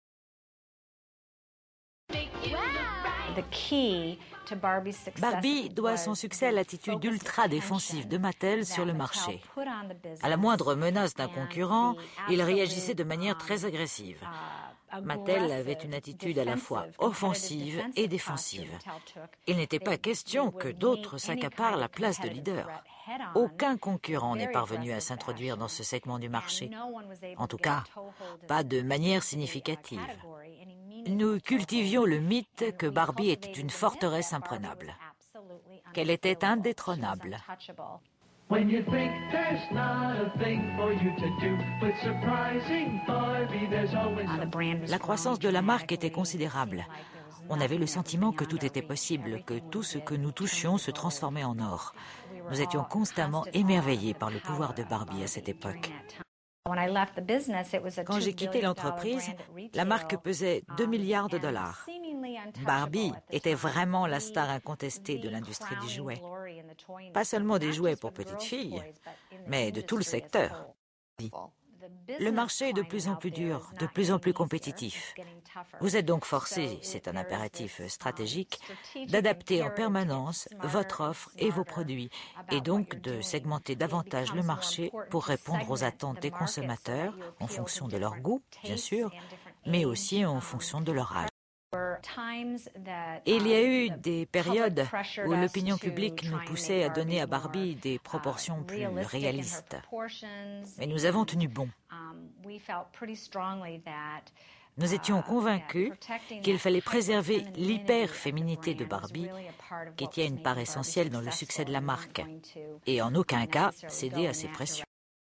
voice over France 5
Voix off